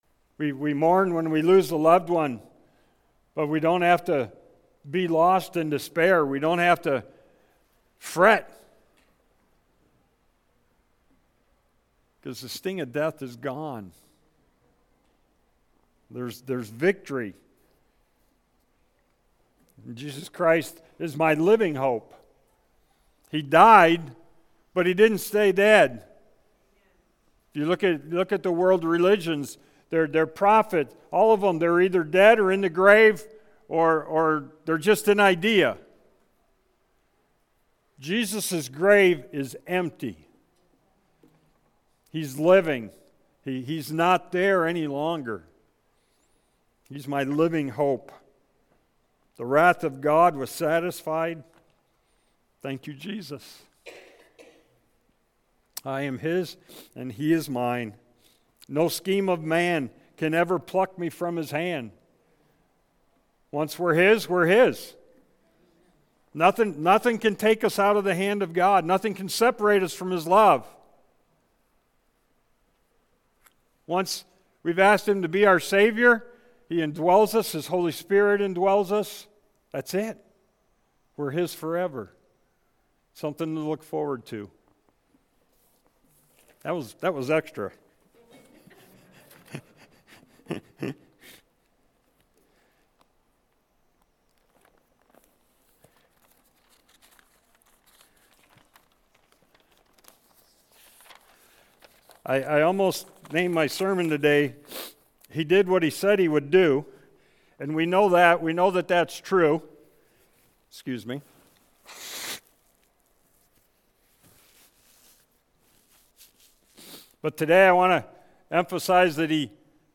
Sermons by First Baptist Church of Newberry Michigan